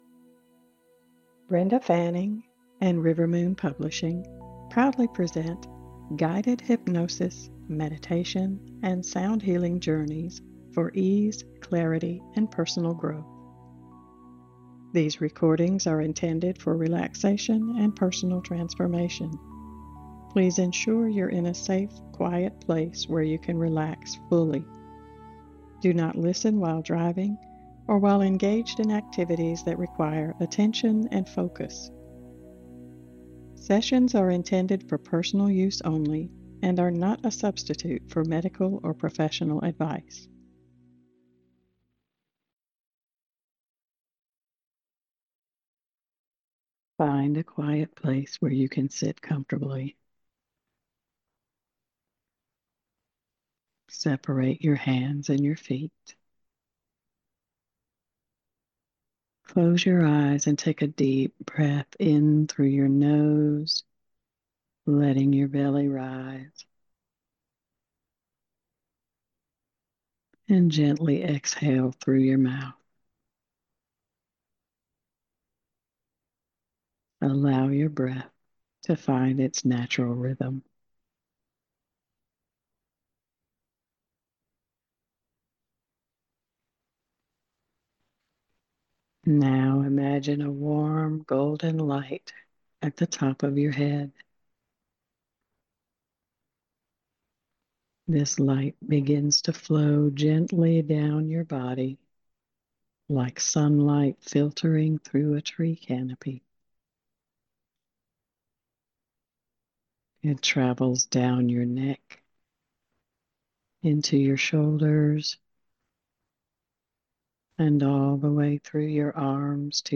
Guided Journeys for Inner Exploration & Self-Discovery
Unlock the transformative power of your mind with the Mystical Excursions collection—a six-session series of expert-guided hypnosis and meditation journeys designed to ground your energy, awaken inner wisdom, and help you navigate life with greater clarity and confidence.